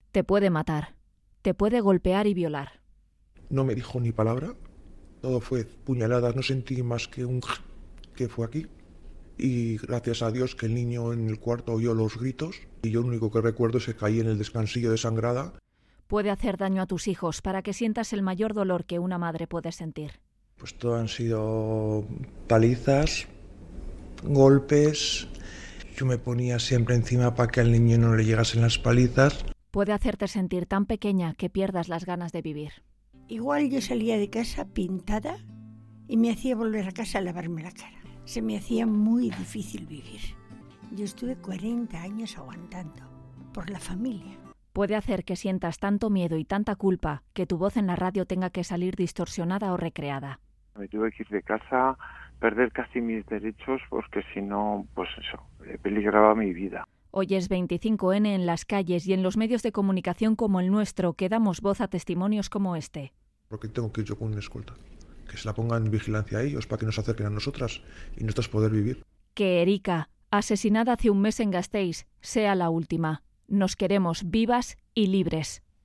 Puede hacer que sientas tanto miedo que tu voz en la radio tenga que salir distorsionada.